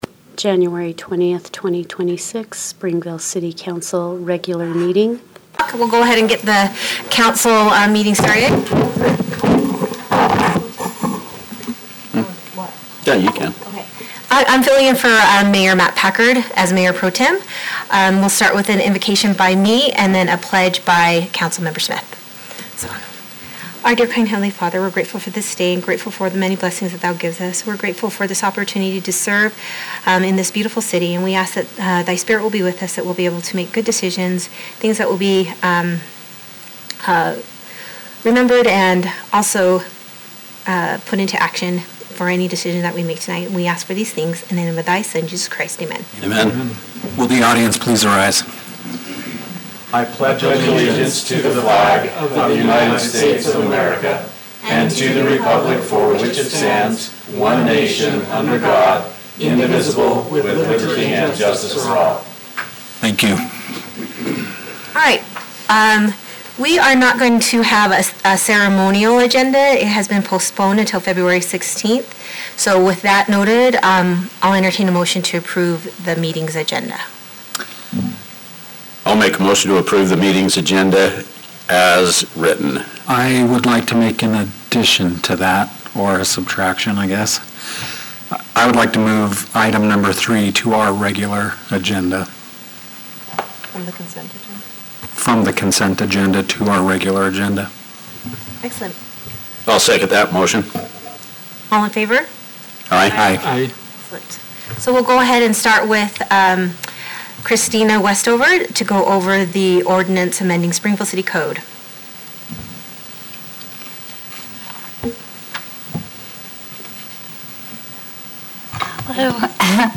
Regular Meeting Agenda
City Council Room